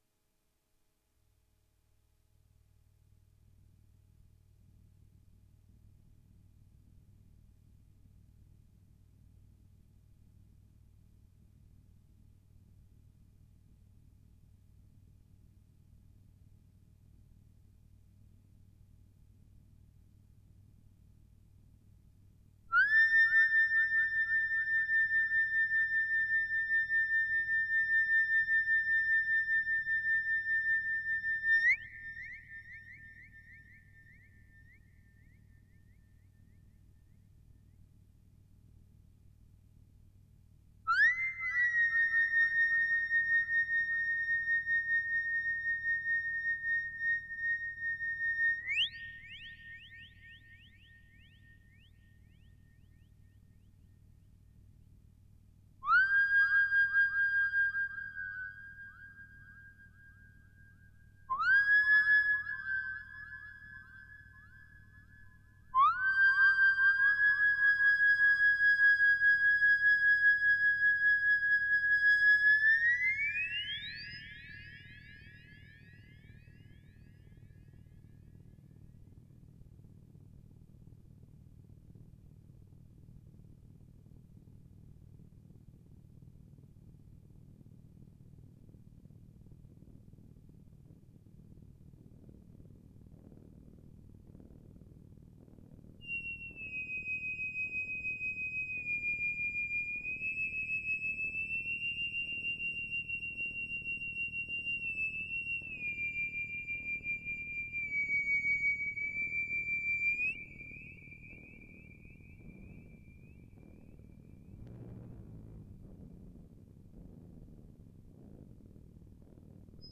Plein de petits moulins et de pulsations non synchronisés
ogg (stéréo)